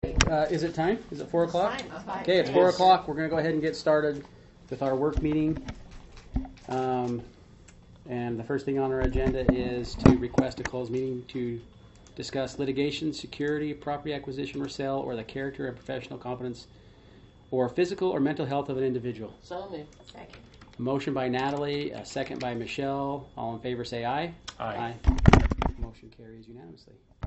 City Council Meeting Agenda
Notice, Meeting, Hearing